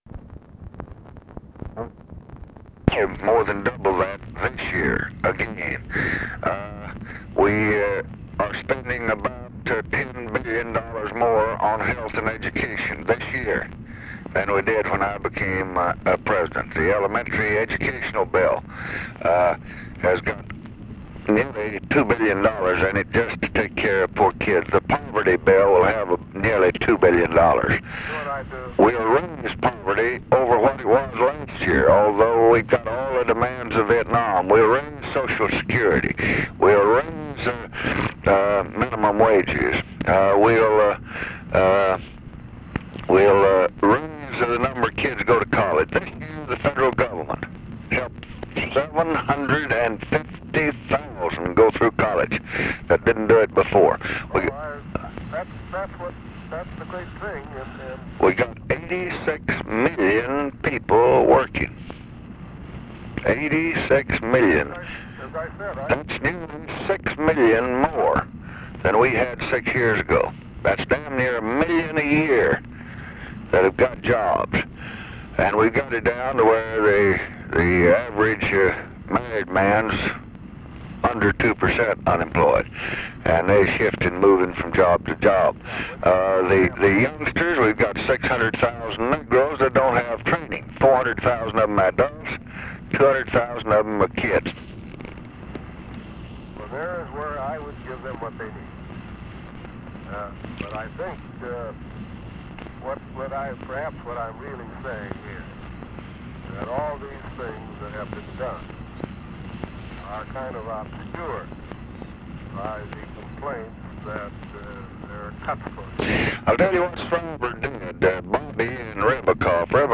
Conversation with ARTHUR GOLDBERG, December 31, 1966
Secret White House Tapes